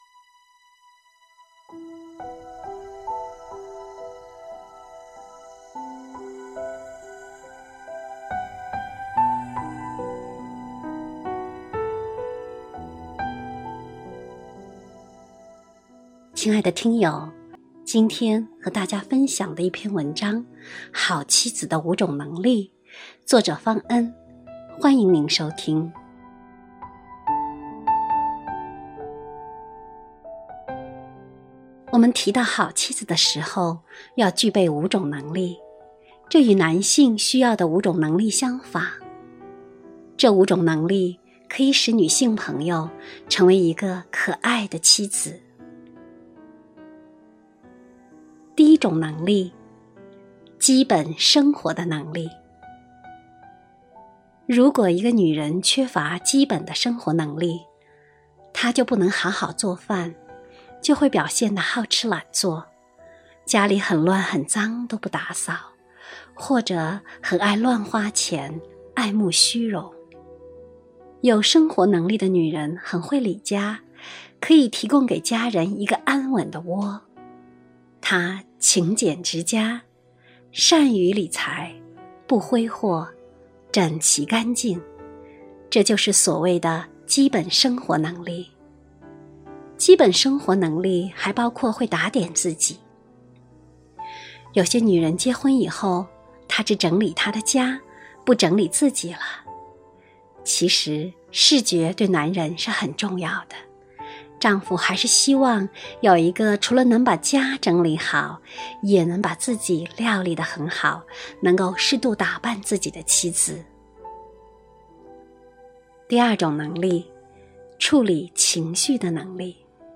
首页 > 有声书 > 婚姻家庭 > 单篇集锦 | 婚姻家庭 | 有声书 > 好妻子的五种能力